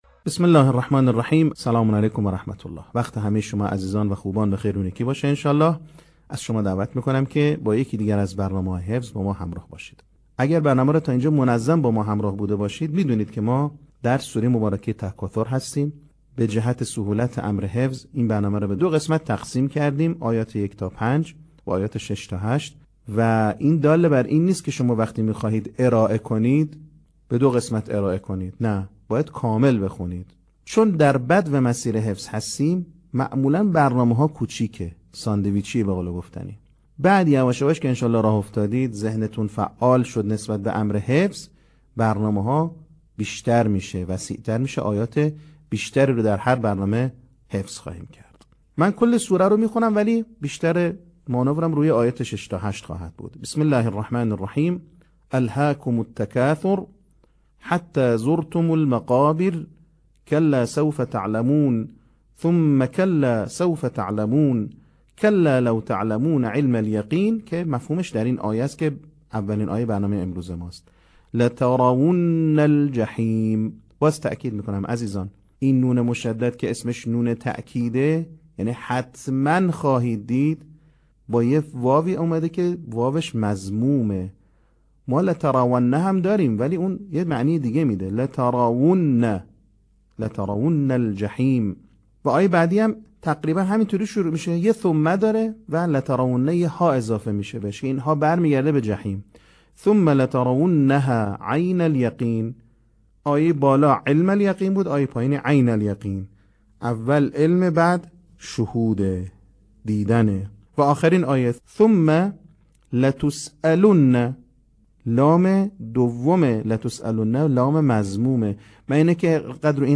صوت | بخش چهارم آموزش حفظ سوره تکاثر